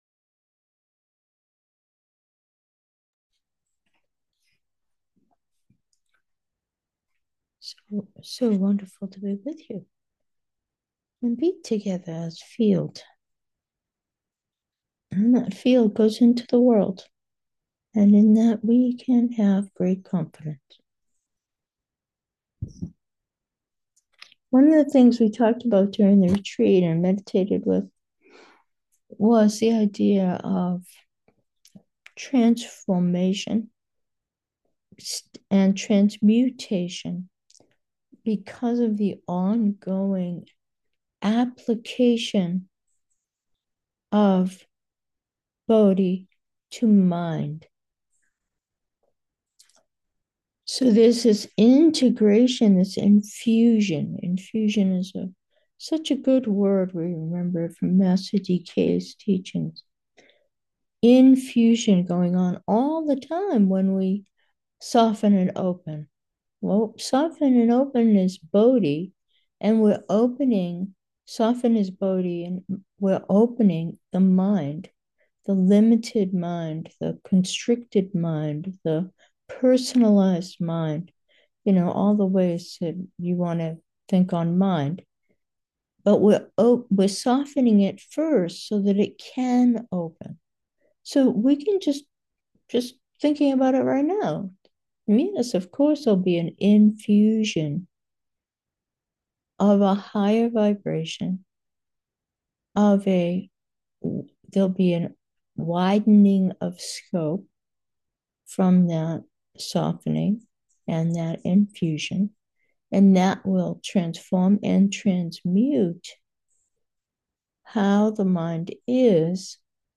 Meditation: alchemy 1 | Blazing Light, Love's Song
We had a two and a half day retreat focused on bodhichitta.